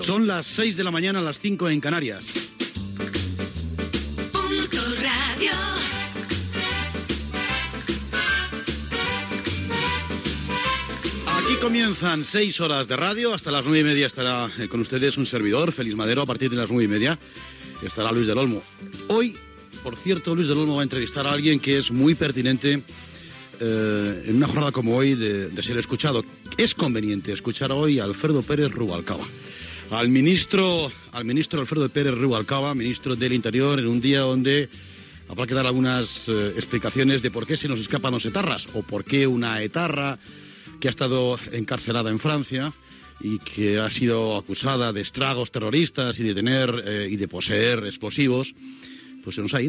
Hora, indicatiu de la ràdio, inici del programa, avenç de qui serà el principal entrevistat del programa
Info-entreteniment
FM